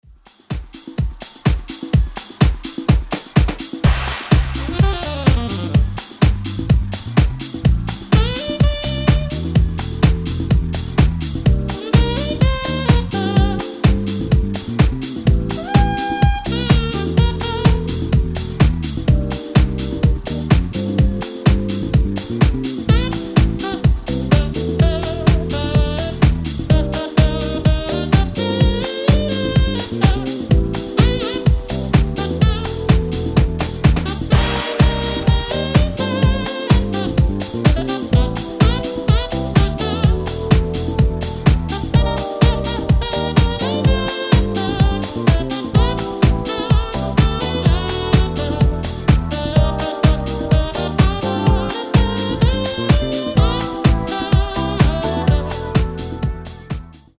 keys
Bass. Fat production provides the energy for a zoo classic.